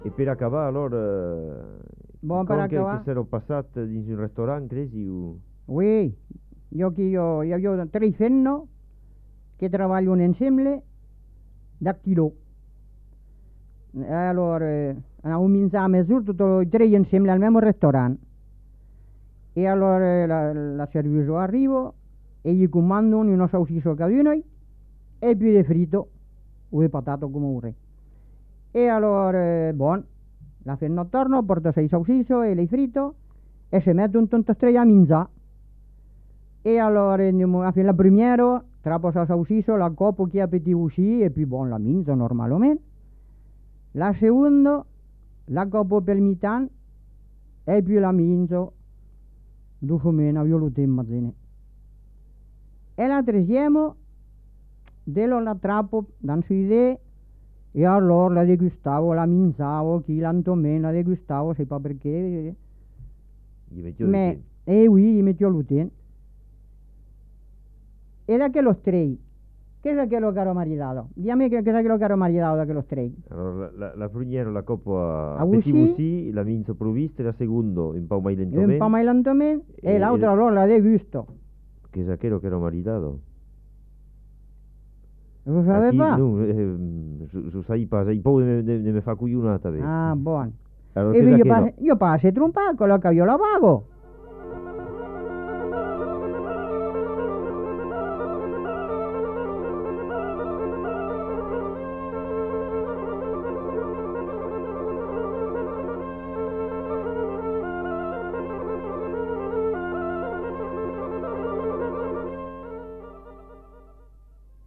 Effectif : 1
Type de voix : voix de femme
Production du son : parlé
Classification : devinette-énigme
Ecouter-voir : archives sonores en ligne